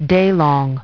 Транскрипция и произношение слова "daylong" в британском и американском вариантах.